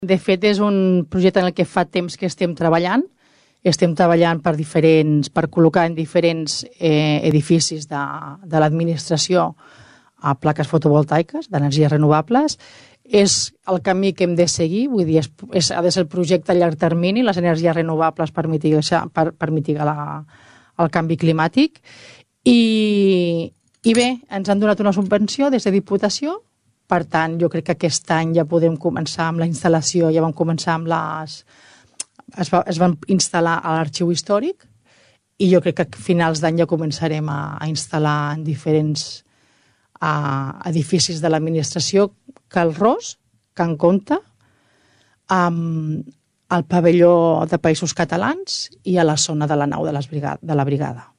L’Ajuntament de Tordera aposta pel consum d’energies renovables a tots els edificis i serveis municipals amb la instal·lació de plaques fotovoltaiques. Ho explica la regidora de medi ambient, Nàdia Cantero.